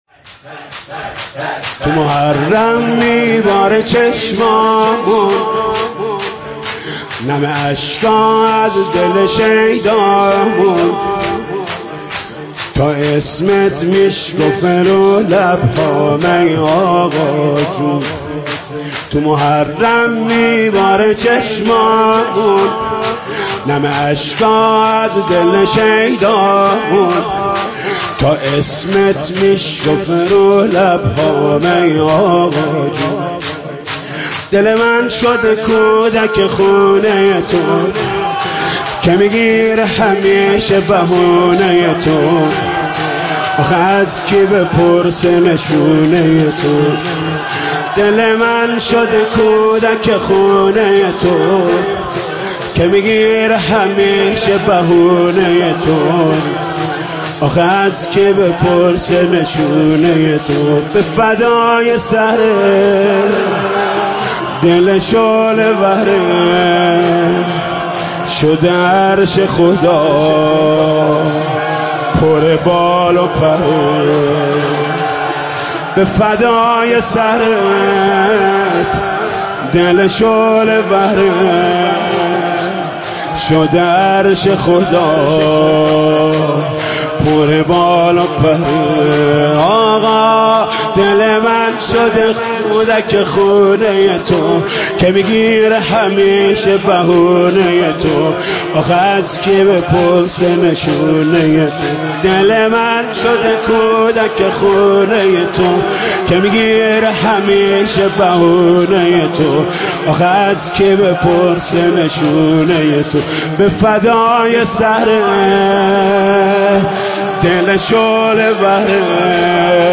شب پنجم محرم 96 محمود کریمی
نوحه جديد محمود کريمی